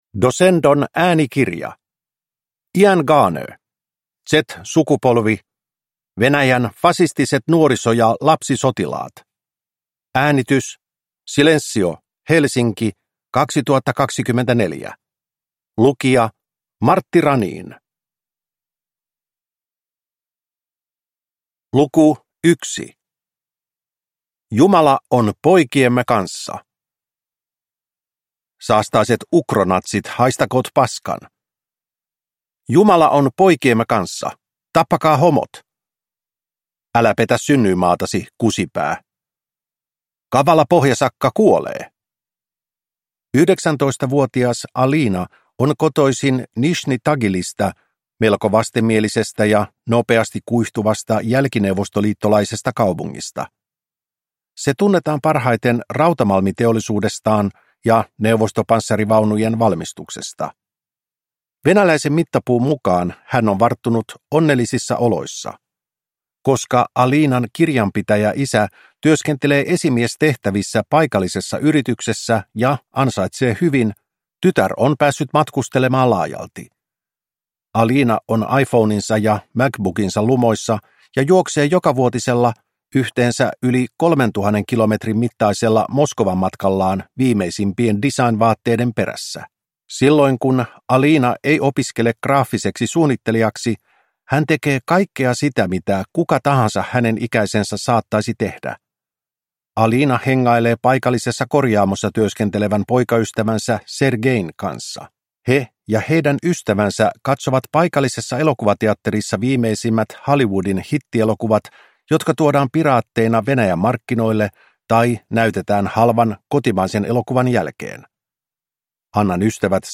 Z-sukupolvi – Ljudbok